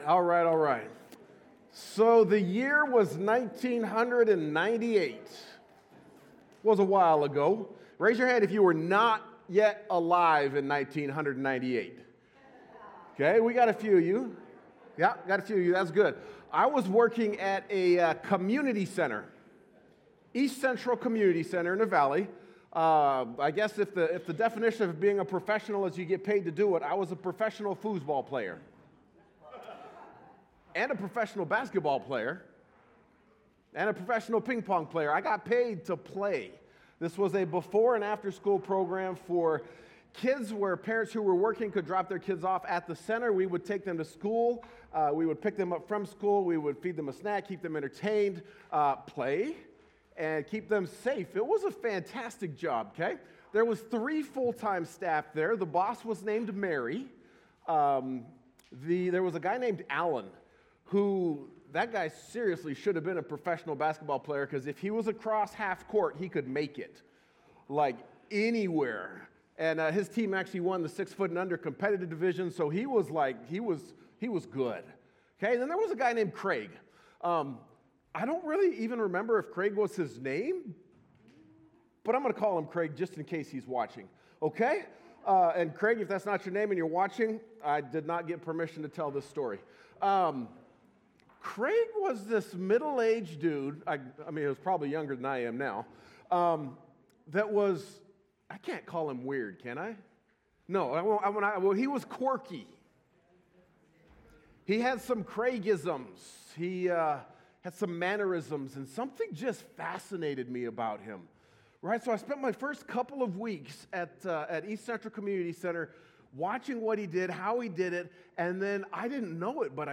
Sermons by First Free Methodist Spokane